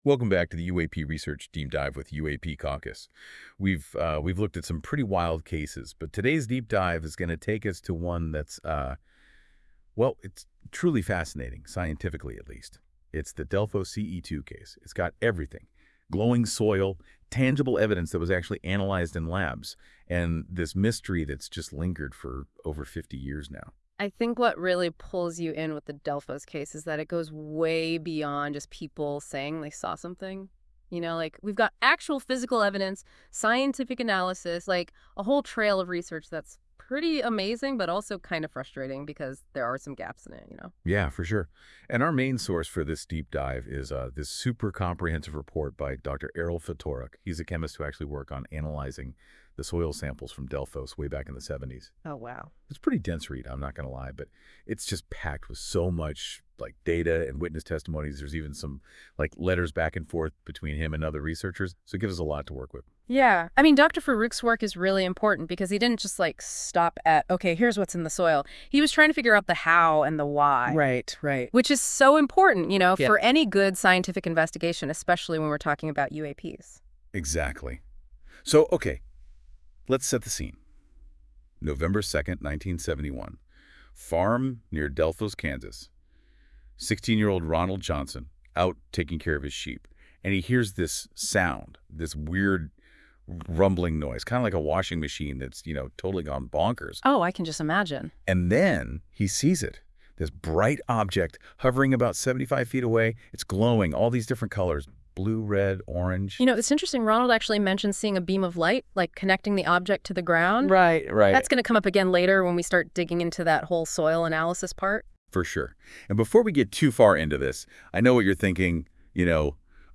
This AI-generated audio may not fully capture the research's complexity.
Audio Summary